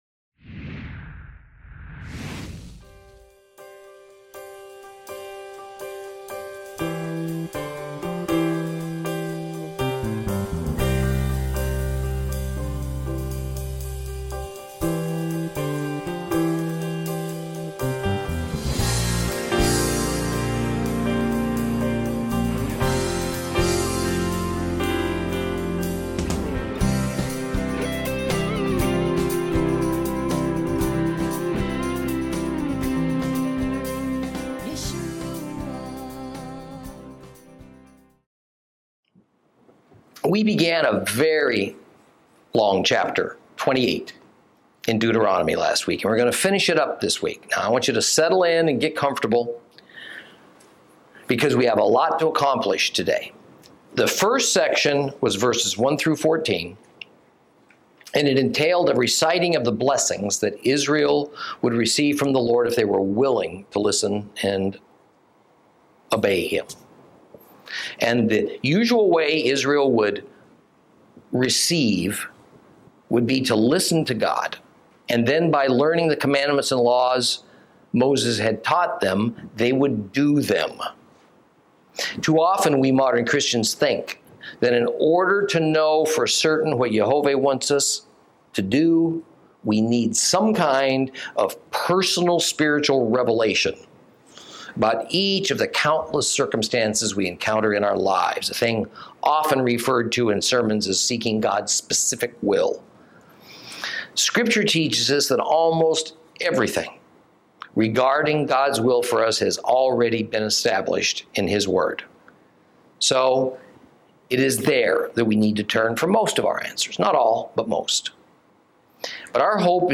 Lesson 39 Ch28 - Torah Class